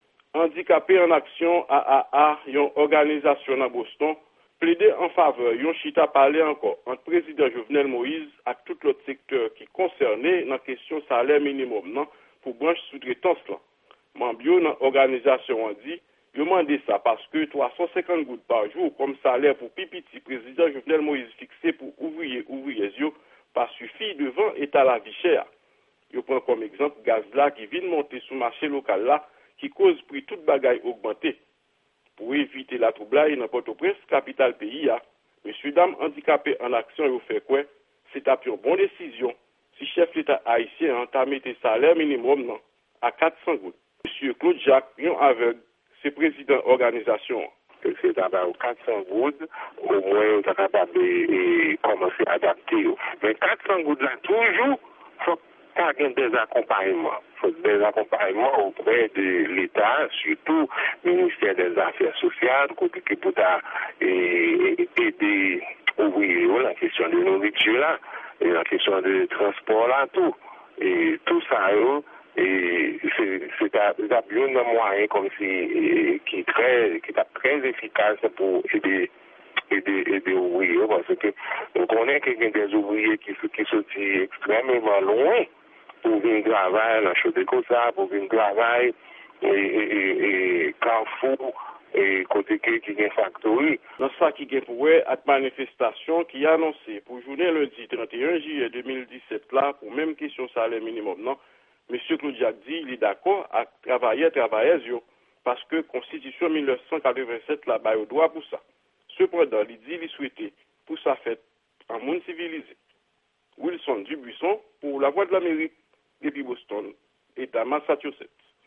Yon repòtaj korespondan Lavwadlamerik